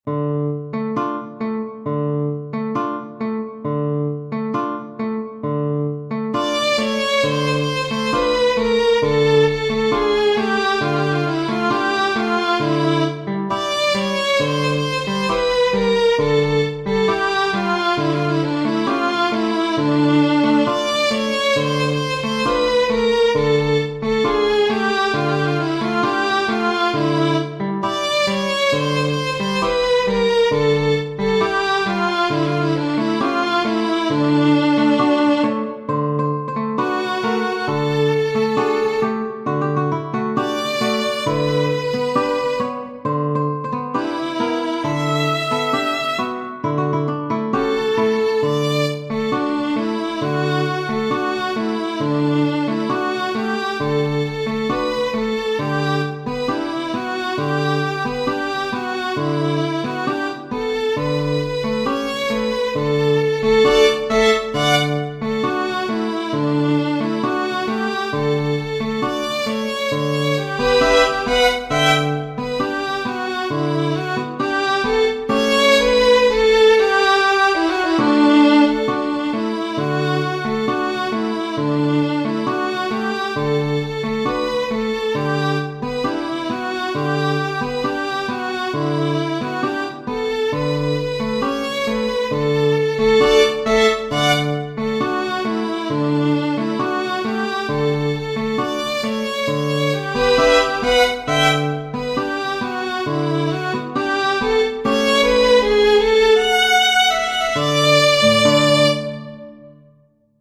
Genere: Opera